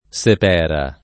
[ S ep $ ra ]